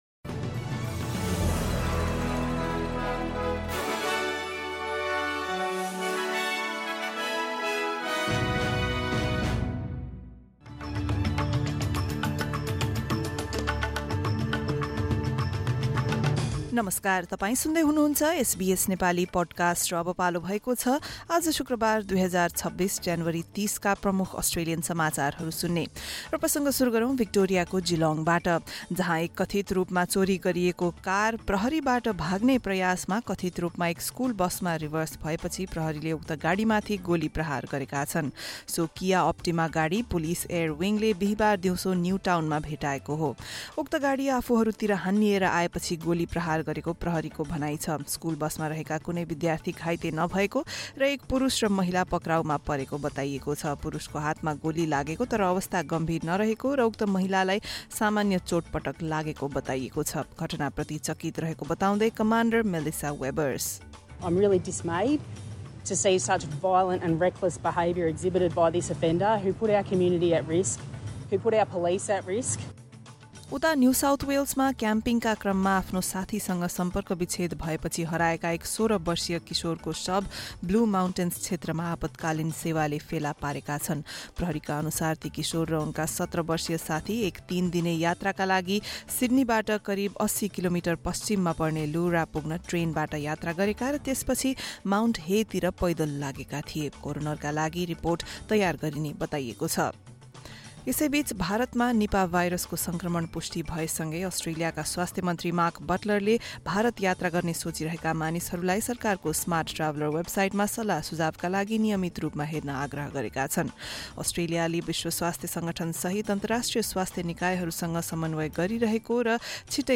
एसबीएस नेपाली प्रमुख अस्ट्रेलियन समाचार: शुक्रवार, ३० ज्यानुअरी २०२६